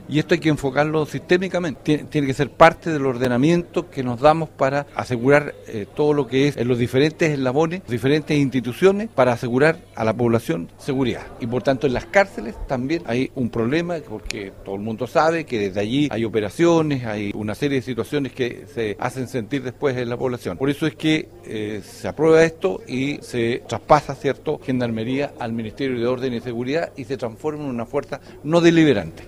Mientras que, el senador del Partido Socialista, Gastón Saavedra, también se refirió a la propuesta aprobada por la Cámara Alta.
CUNA-SENADOR-SAAVEDRA-.mp3